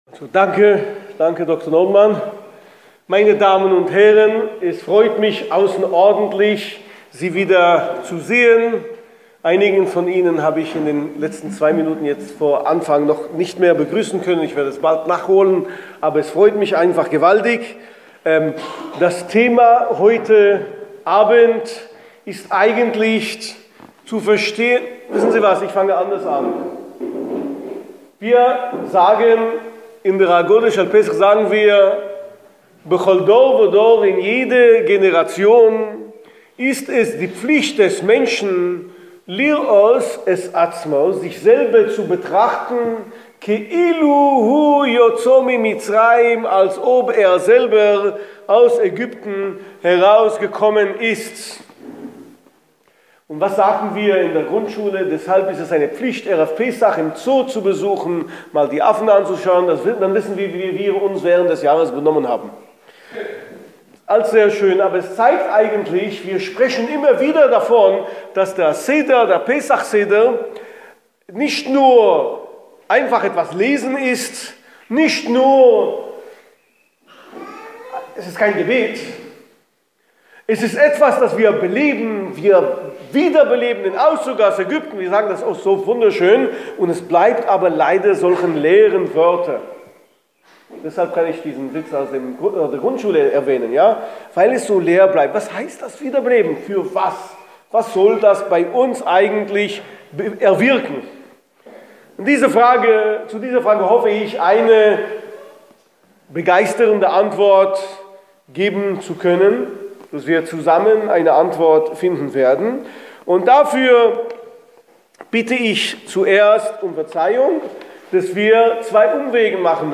Warum gingen unsere Vorfahren ins ägyptische Exil? (Audio-Schiur)